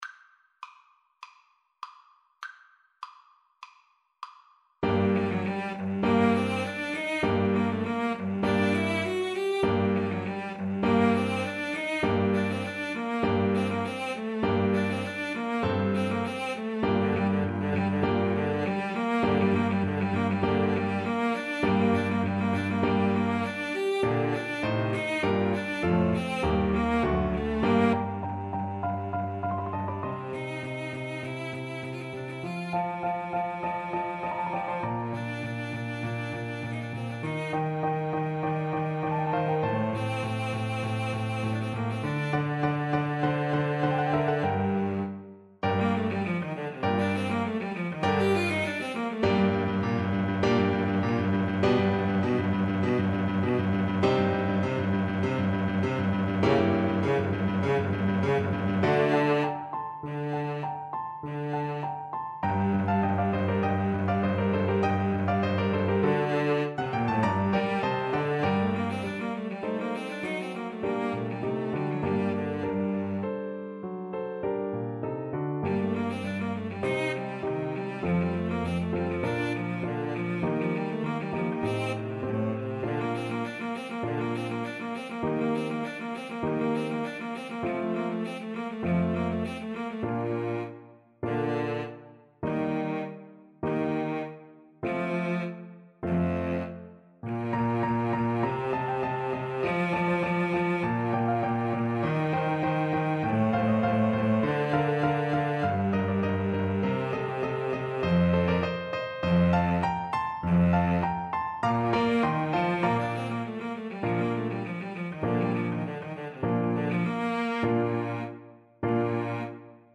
4/4 (View more 4/4 Music)
Allegro (View more music marked Allegro)
Cello Duet  (View more Advanced Cello Duet Music)
Classical (View more Classical Cello Duet Music)